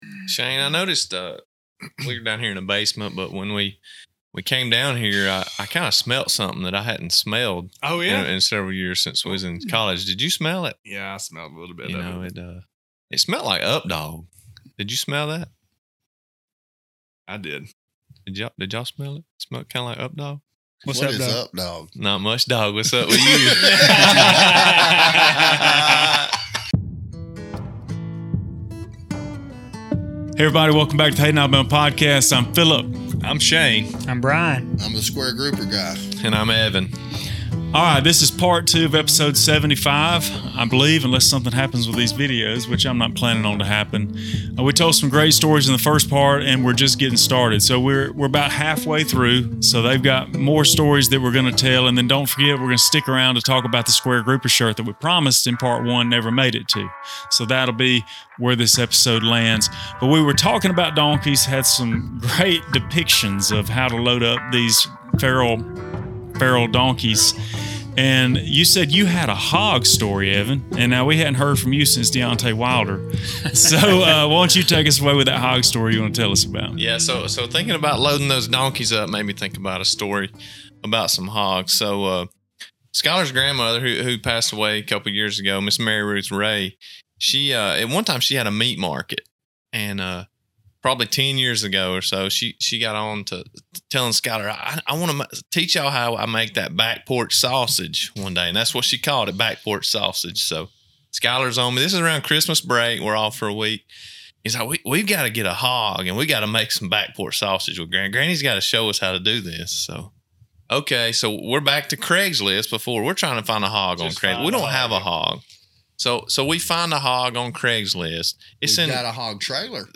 Join us for a wild ride as five southern Alabama boys gather to share some of their most outrageous stories! In this episode, you'll hear all about a thrilling hog killing adventure, complete with all the excitement and chaos you can imagine. Then, hold on tight as they recount the adrenaline-pumping experience of running from the cops on 4-wheelers through the backwoods.